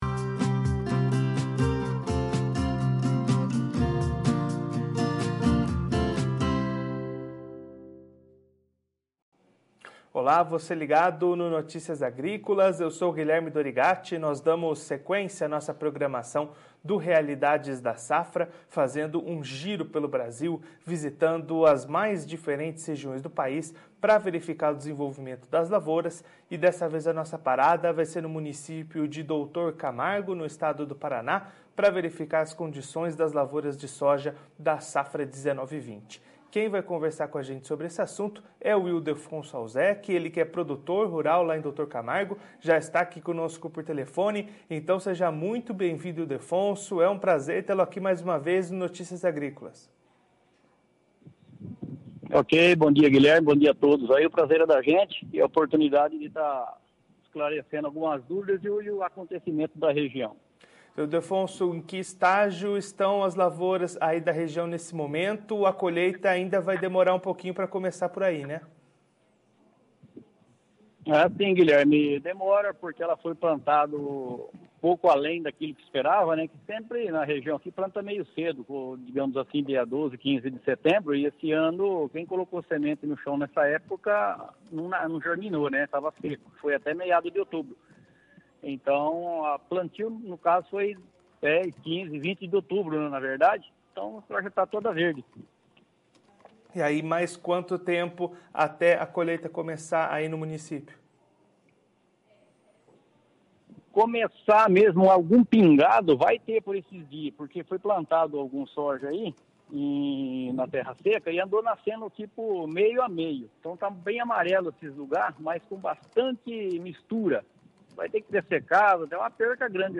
Confira a entrevista completa com o produtor rural de Doutor Camargo/PR no vídeo.